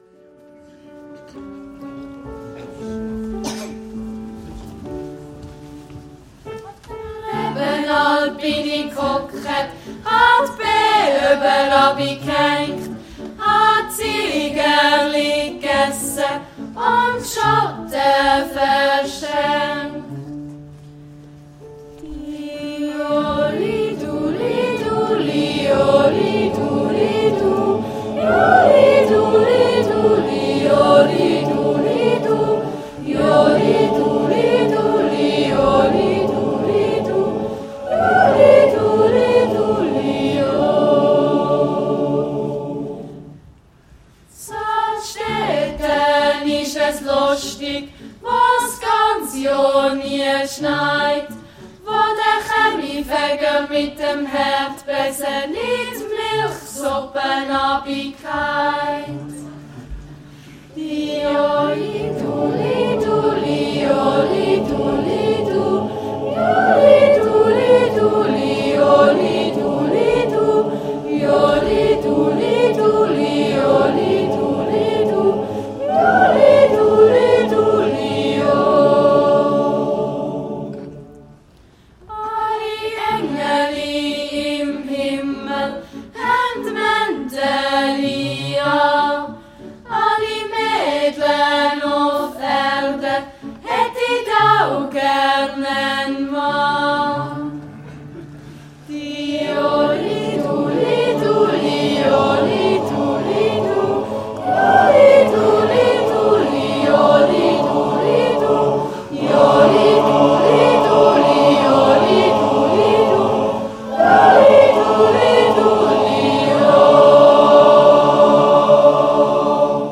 Gesamtbilder von unserem Chor seit 2010 bis ... heute
Schau dir die Bilder in Ruhe an und höre dazu unsere Melodien von der Serenade 2013 (unten) und vom Maikonzert 2015 (oben)